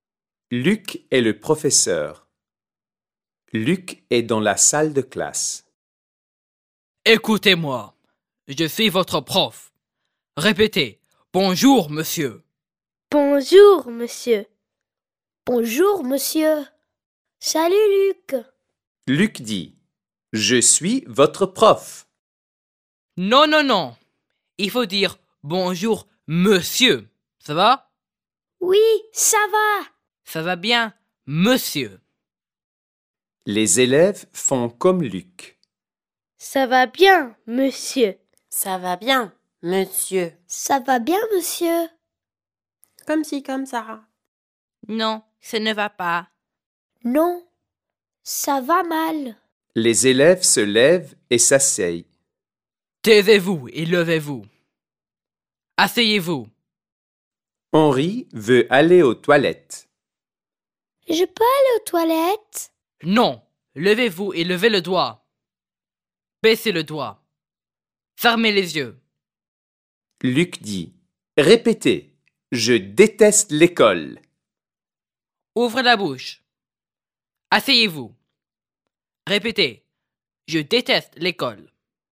To help non-specialist teachers, model good pronunciation and provide valuable practice in listening skills, the book comes with free audio downloads and English translations of the stories.